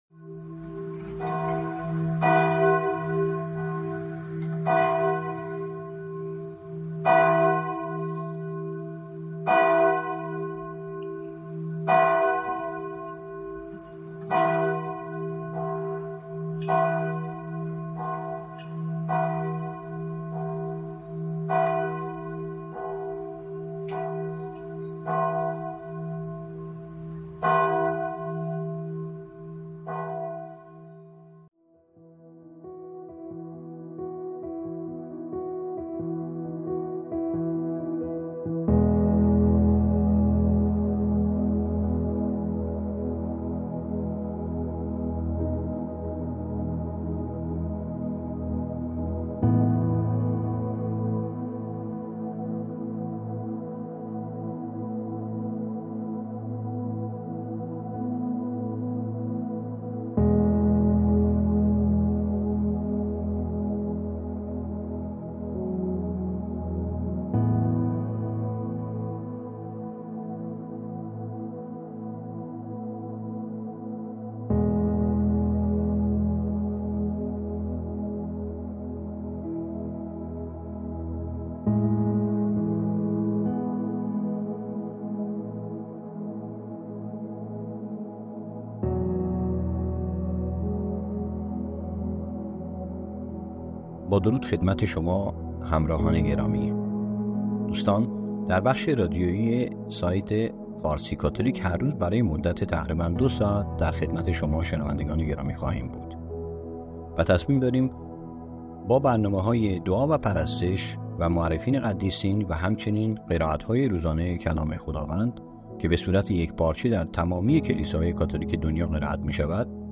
حدود دو ساعت برنامه های صوتی روزانه فارسی کاتولیک به شکل فایل، شامل انواع دعا،قرائت های کلیسایی و... برای شما عزیزان آماده گشته است